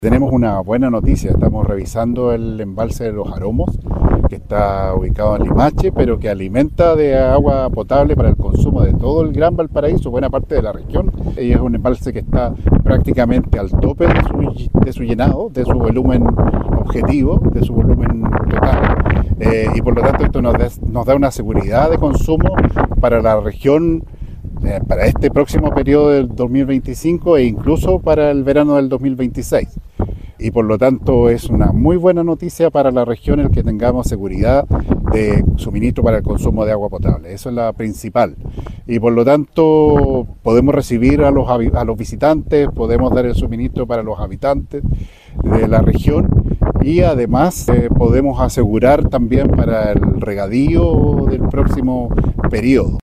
El Delegado Presidencial Regional, Yanino Riquelme, comentó lo siguiente.